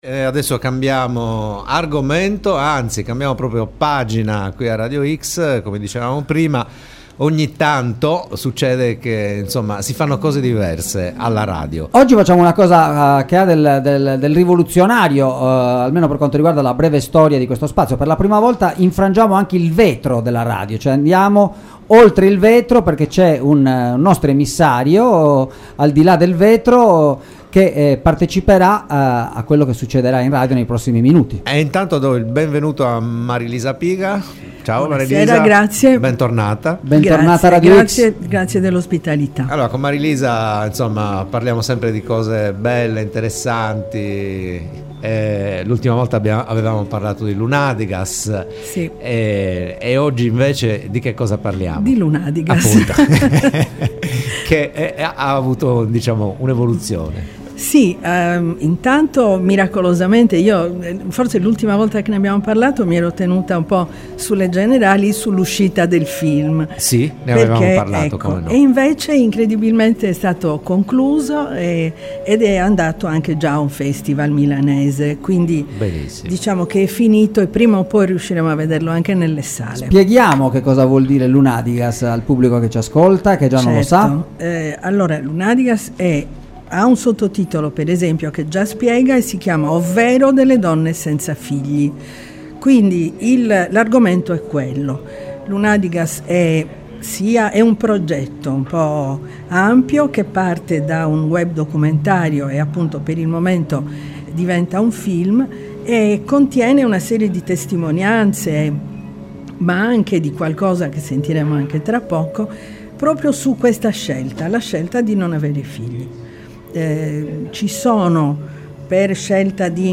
ASCOLTA L’INTERVISTA E I MONOLOGHI IMPOSSIBILI